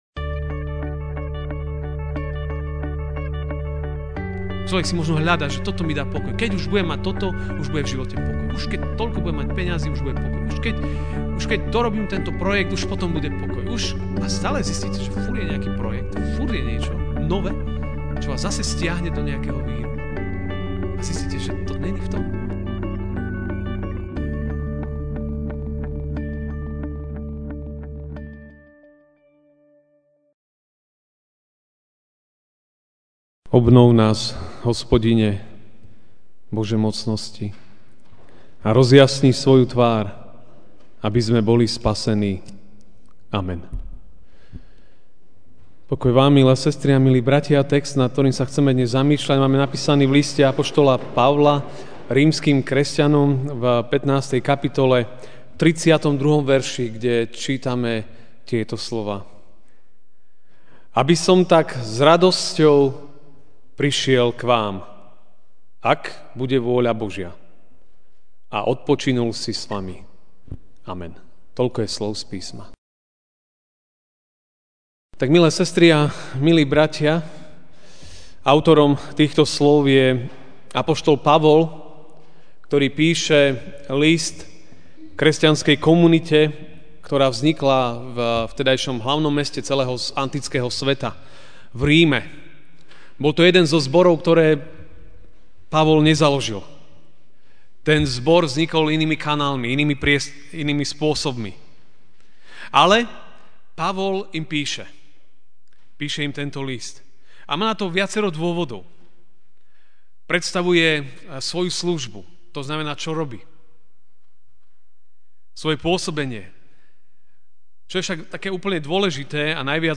MP3 SUBSCRIBE on iTunes(Podcast) Notes Sermons in this Series Ranná kázeň: Radostný odpočinok!